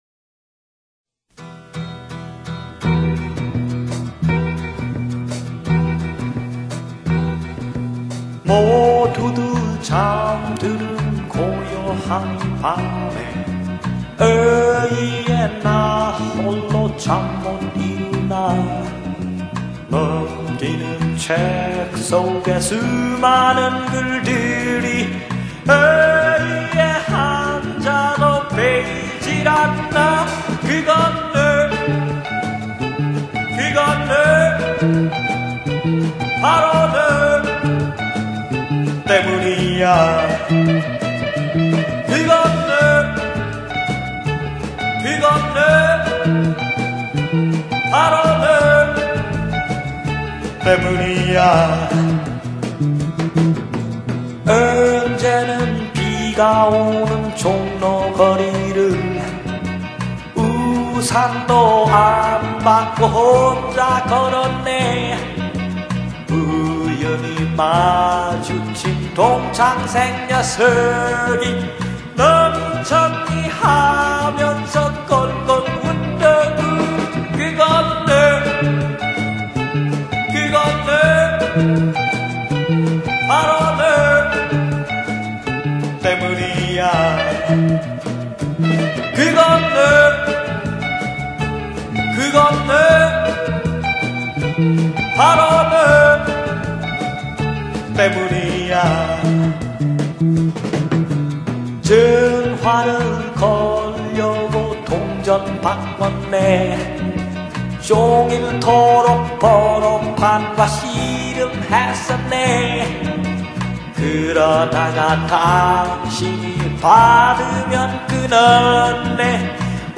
포크 록